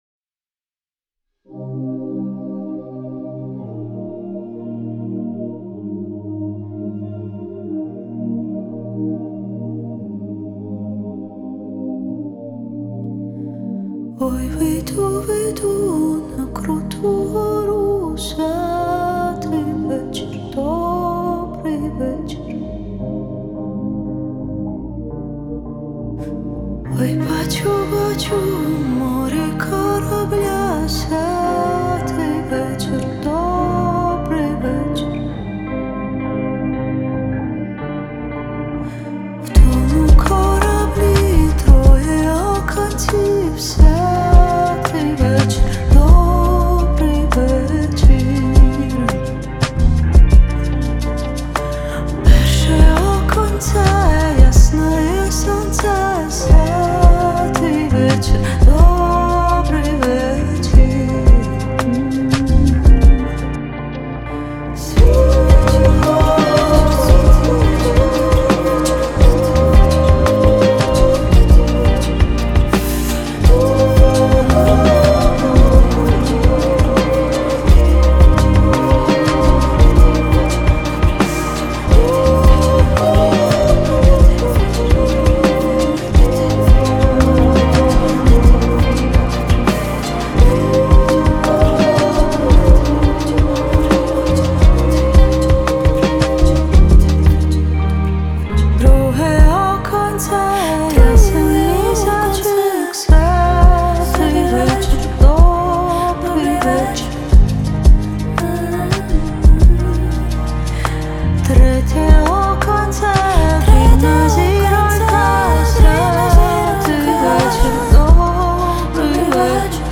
• Жанр: Folk